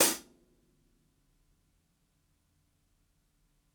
ROOMY_HH_1.wav